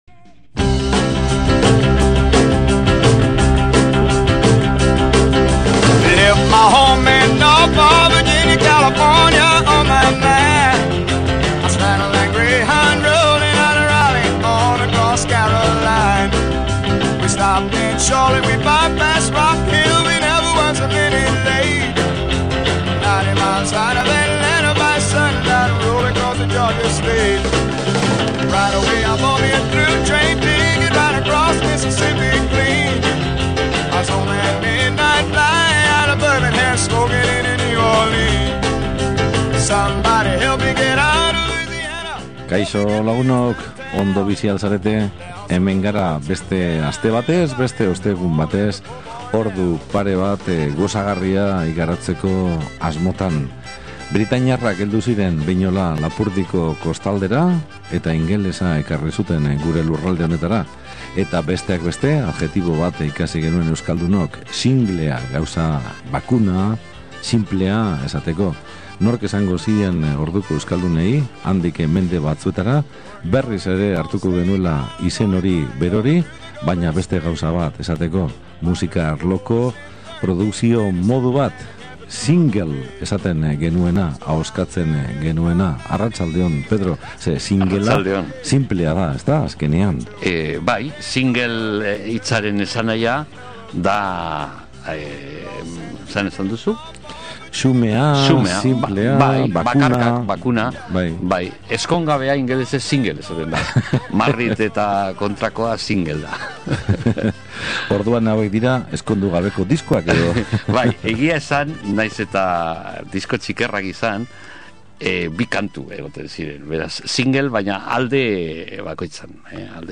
Musikaren historian egiazko harribitxiak diren binilo txikiak ekarri eta gure gure disko jogailu zaharrean entzun ditugu beste behin.